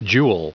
Prononciation du mot jewel en anglais (fichier audio)
Prononciation du mot : jewel